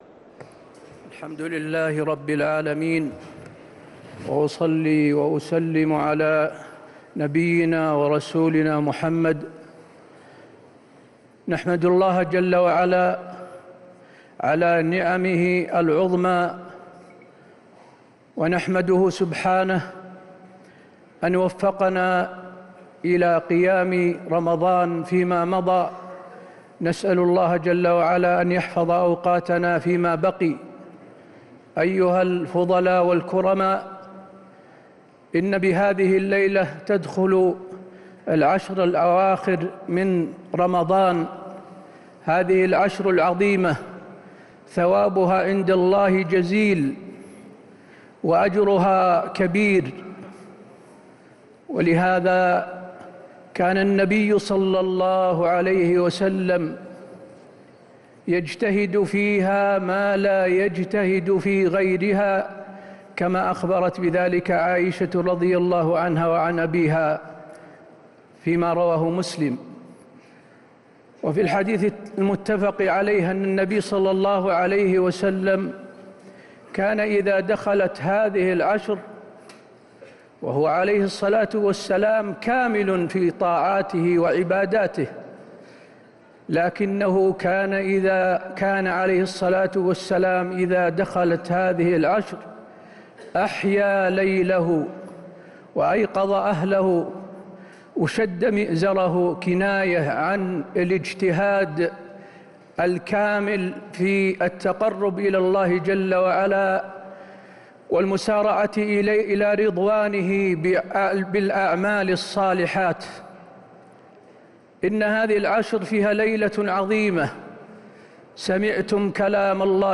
كلمة الشيخ حسين آل الشيخ بمناسبة حلول العشر الأواخر بعد صلاة العشاء 20 رمضان 1446هـ > كلمات أئمة الحرم النبوي 🕌 > المزيد - تلاوات الحرمين